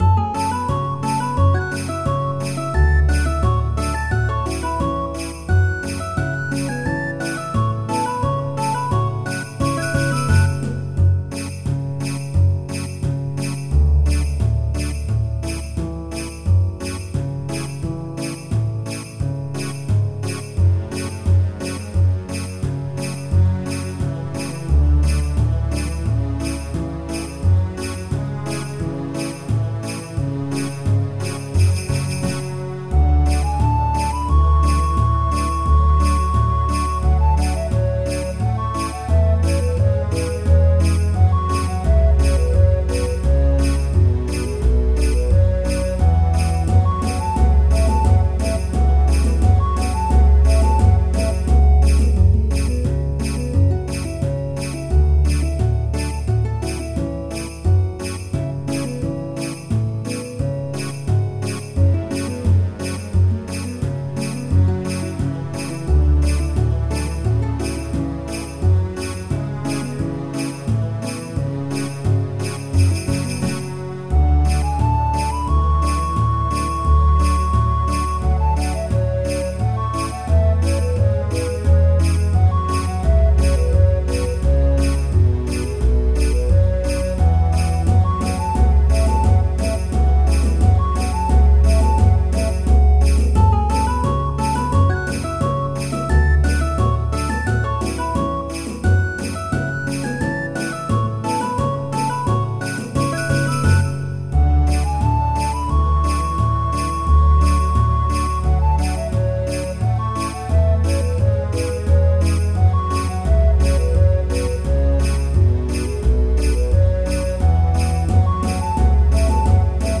自制karaoke
非消音，听写的。